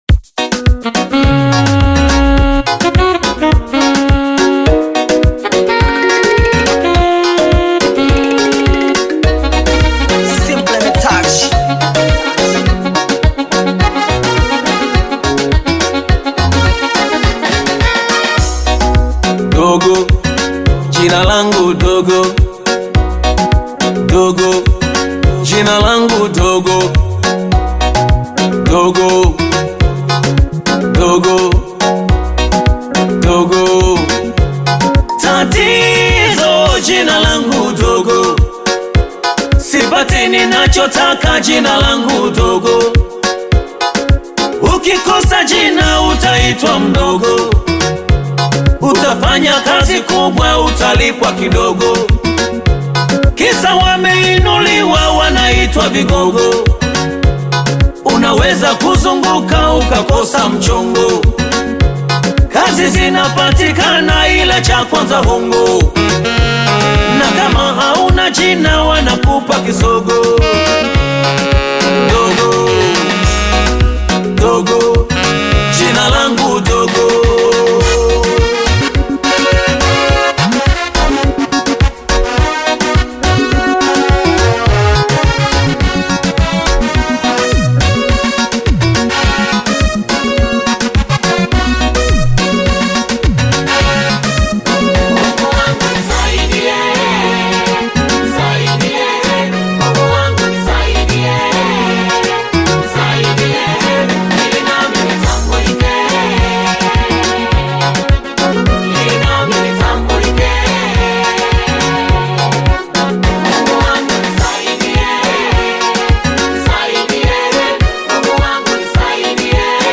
Gospel music track
Tanzanian Gospel